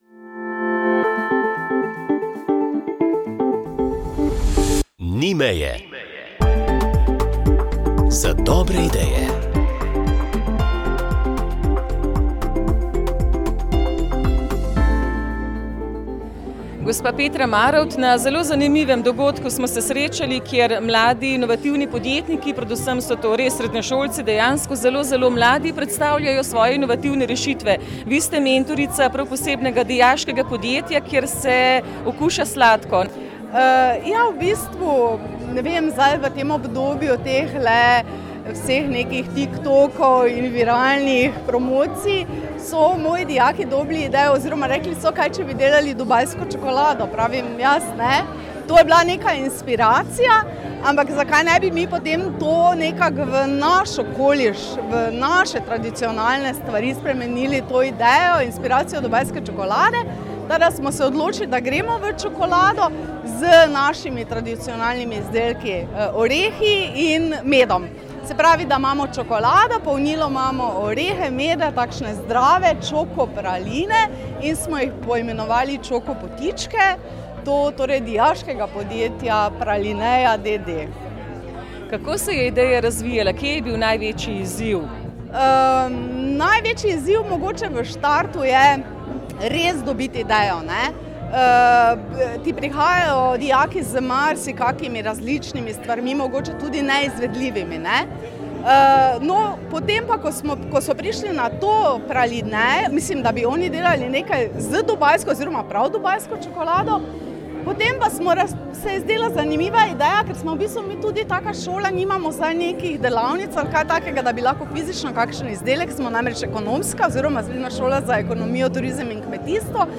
V oddaji so sodelovali poslušalci s svojimi pričevanji in izkušnjami ob smrti bližnjih.